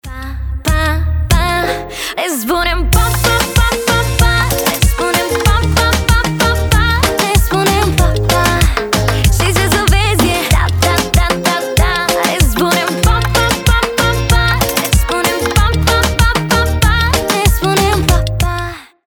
• Качество: 320, Stereo
заводные
румынские